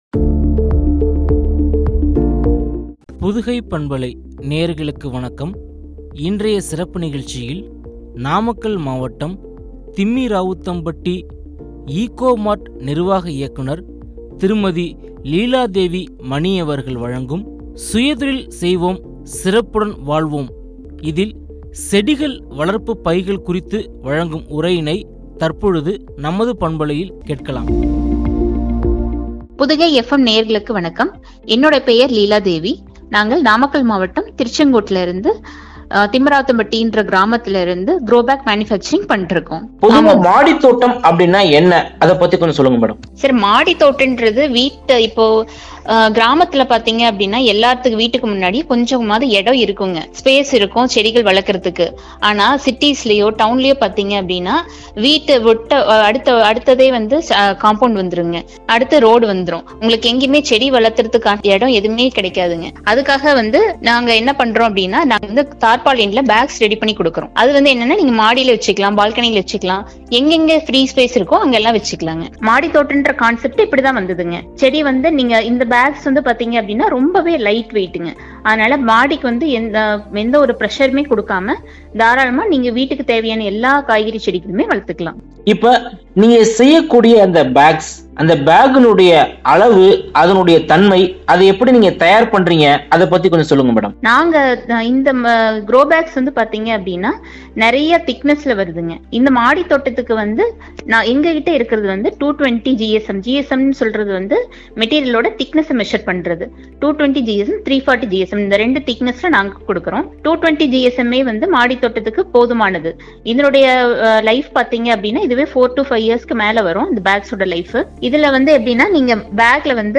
( செடி வளர்ப்பு பைகள் ) குறித்து வழங்கிய உரையாடல்.